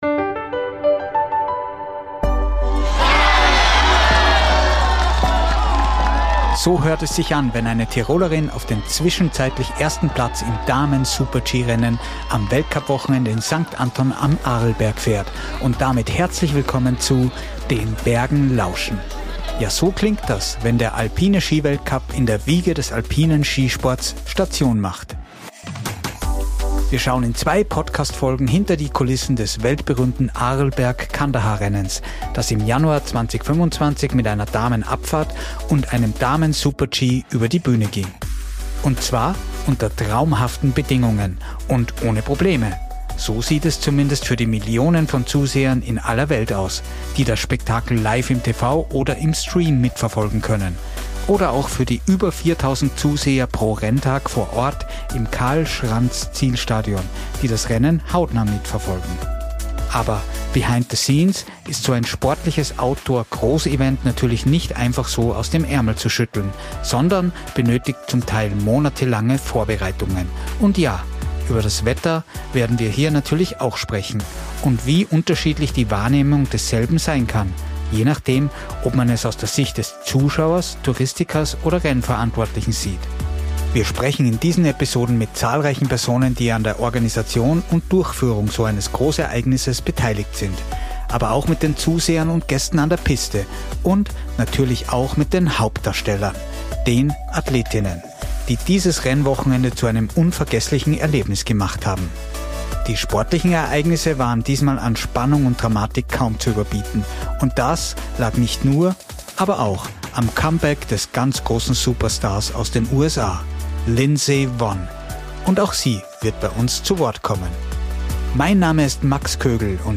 Die erste Folge dieser zweiteiligen Serie gibt exklusive Einblicke hinter die Kulissen: von der Rennvorbereitung über logistische Herausforderungen bis hin zur Bedeutung des Wetters für ein Outdoor-Sportevent. Zahlreiche Beteiligte aus dem Organisations-Team, dem Tourismusverband sowie Athletinnen selbst schildern ihre Perspektiven. Ein besonderes Highlight des Wochenendes: das mit Spannung erwartete Comeback von Lindsey Vonn, die in dieser Folge ebenfalls zu Wort kommt.